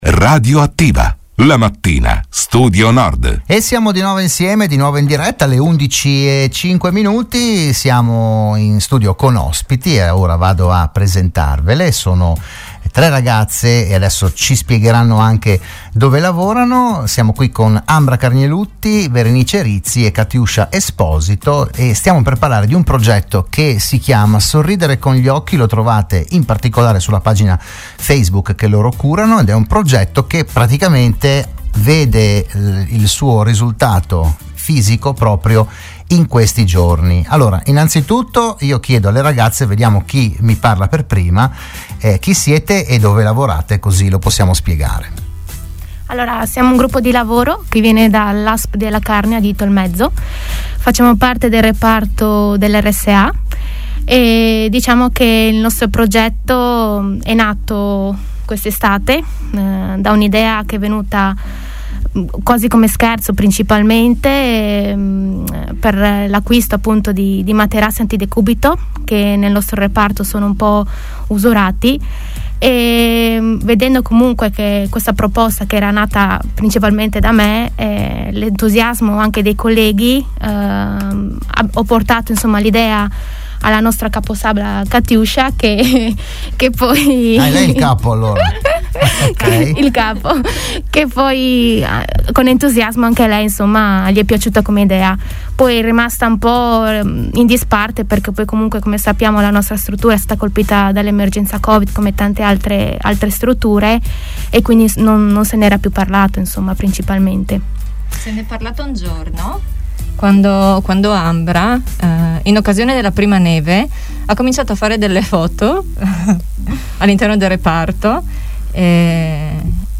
L'AUDIO e il VIDEO dell'intervento a Radio Studio Nord